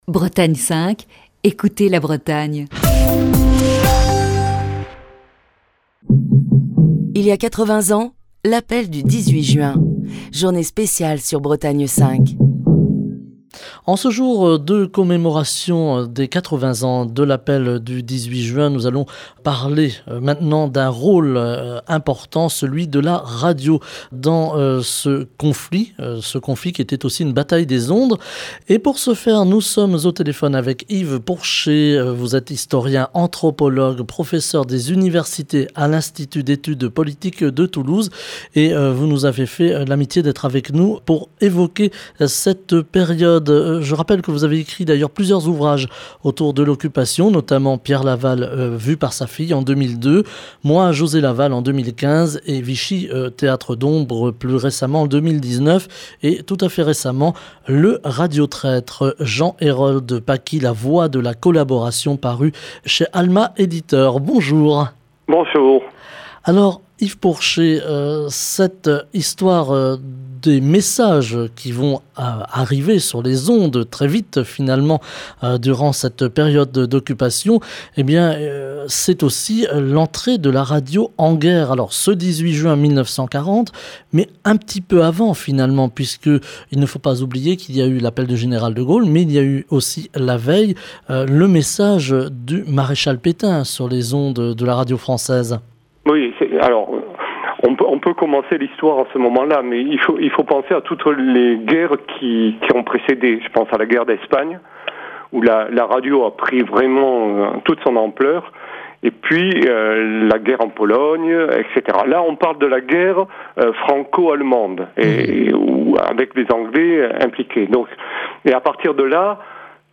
Émission du 18 juin 2020. Journée spéciale 80 ans de l'appel du 18 juin.